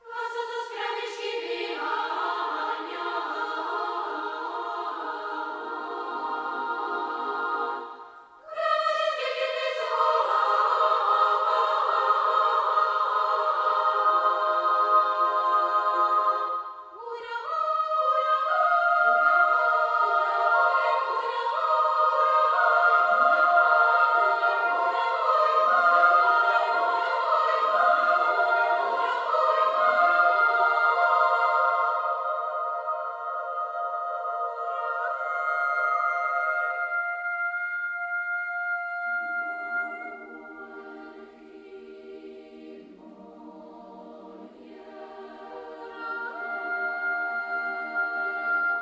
adaptation of folk song